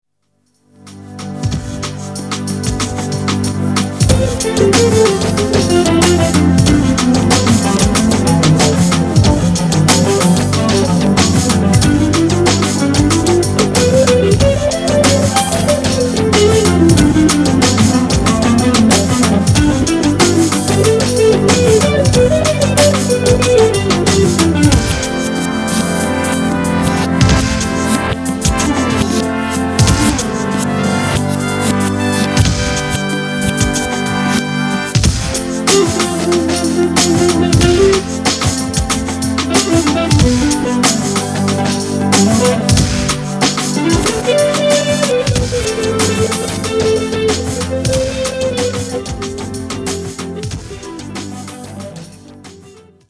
Solo de guitare électrique (guitare Gibson)
musiques instrumentales